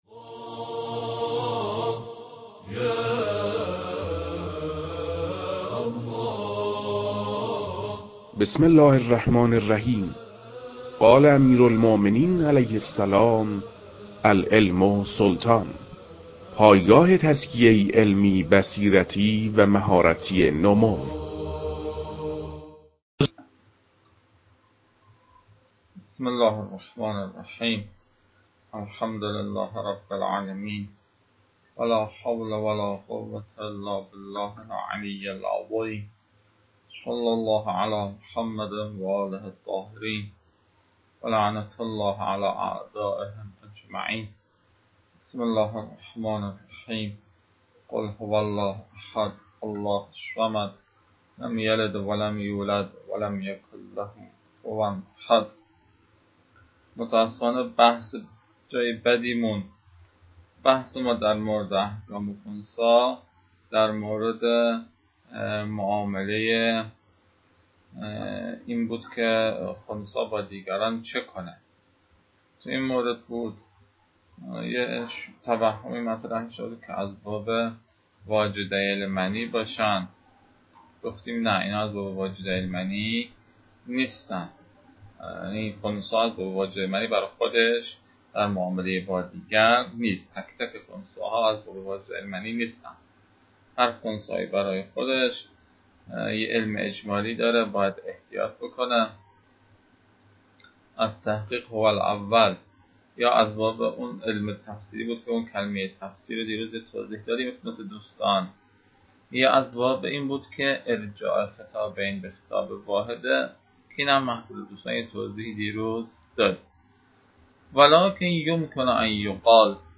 تدریس